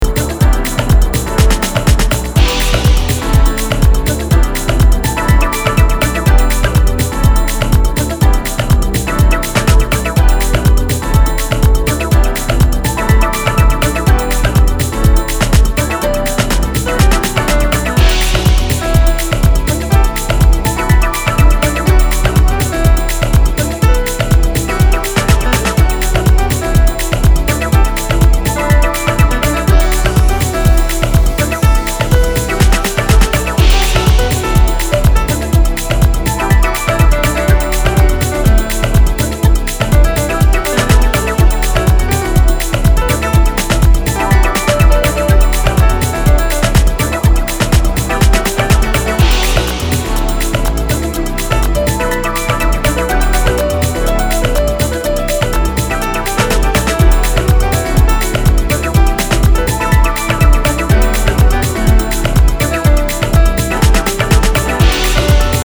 Instrumental Remix